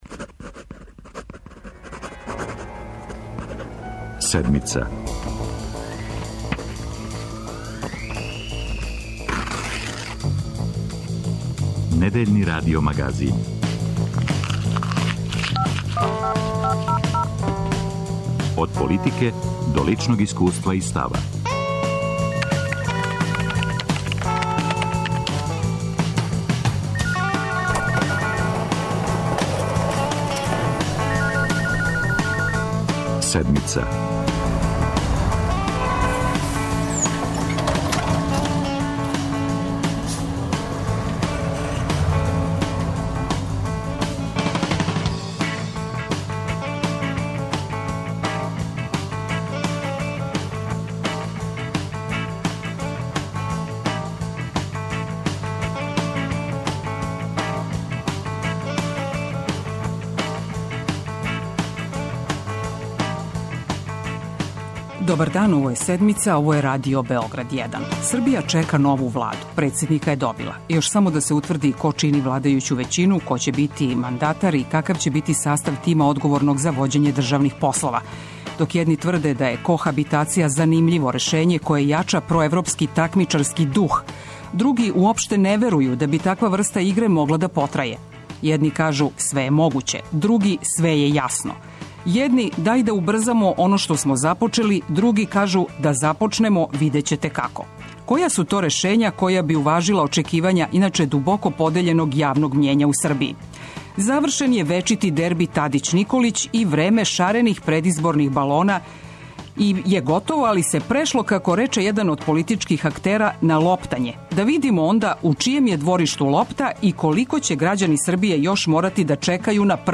Гост емисије је Славица Ђукић Дејановић вршилац дужности председника Републике Србије, актуелни председник Народне скупштине и потпредсеник Социјалистичке партије Србије.